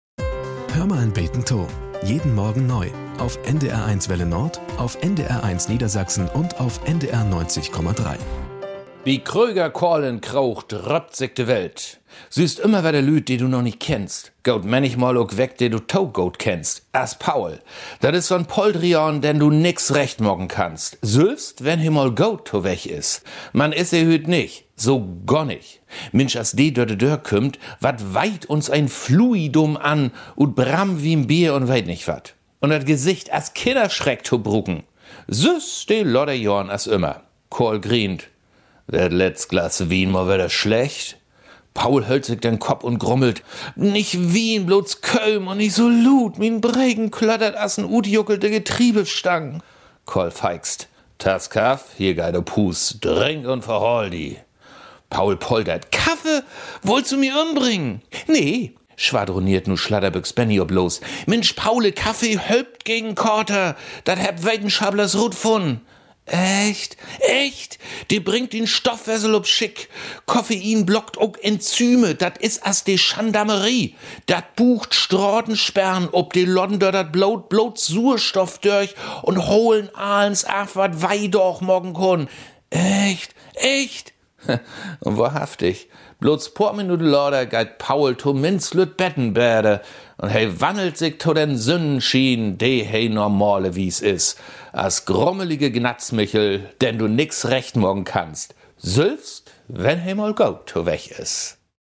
Nachrichten - 29.03.2023